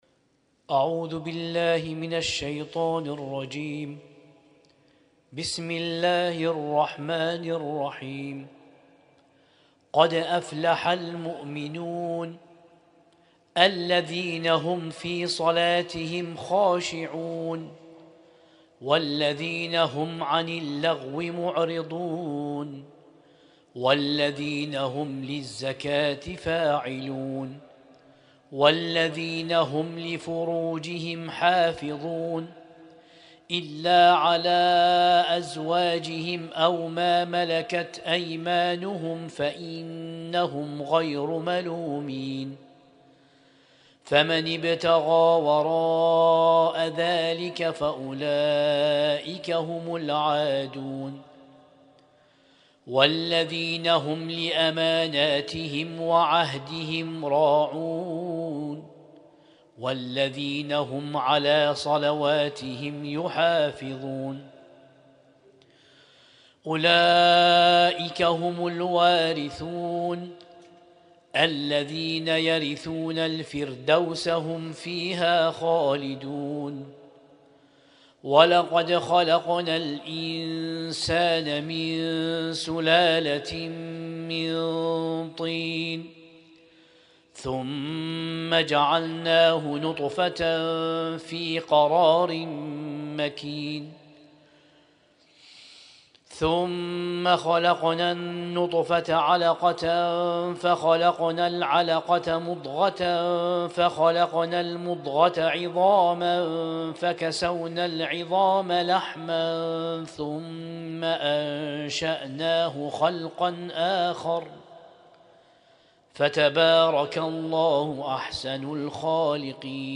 ليلة 18 من شهر رمضان المبارك 1447هـ